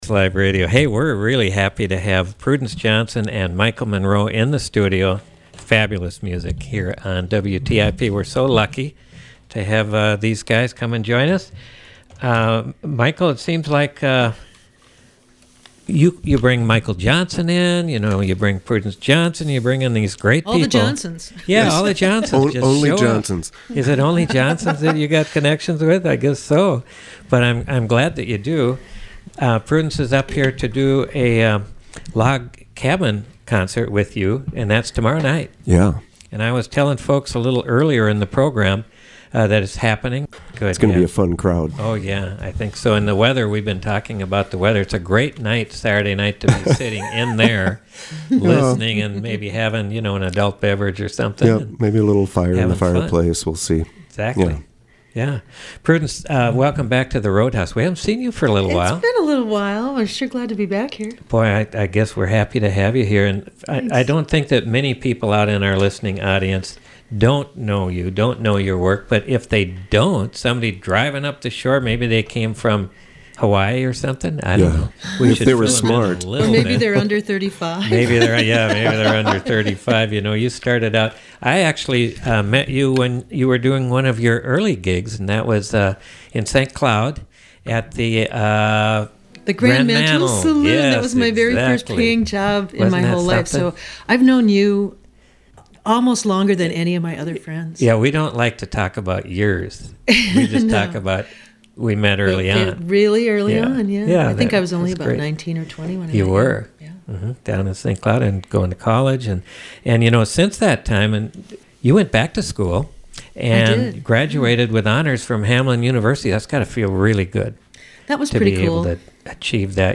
Listen for fun conversation and some gorgeous sound.
Live Music Archive